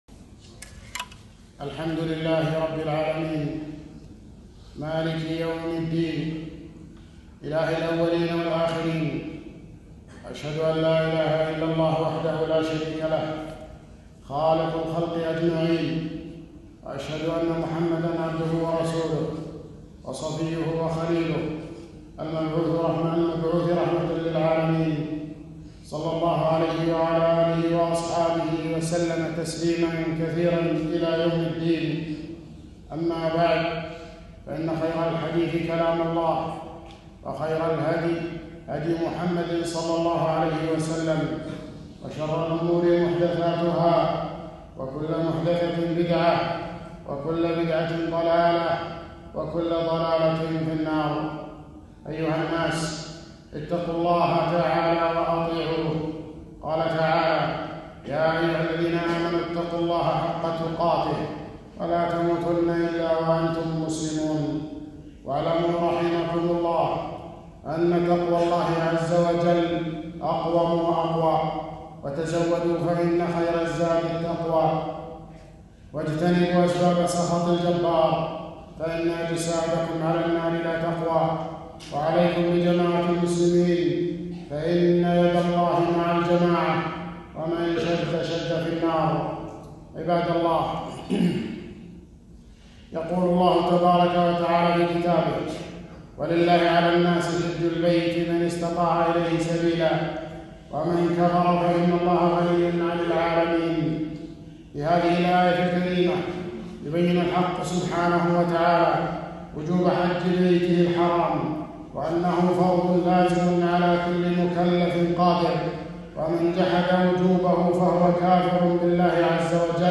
خطبة - الحج ركن الاسلام الخامس